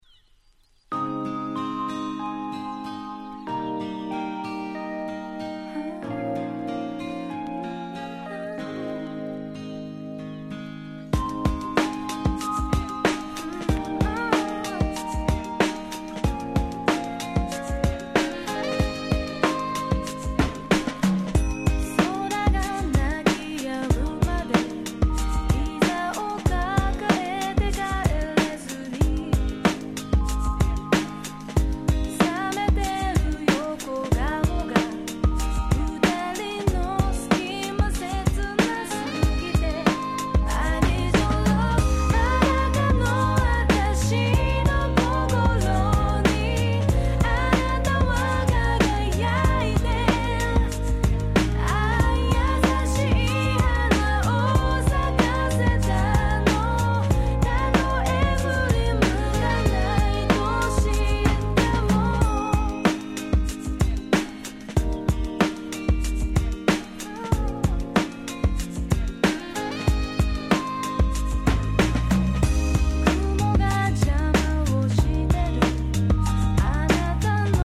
98' Nice Japanese R&B !!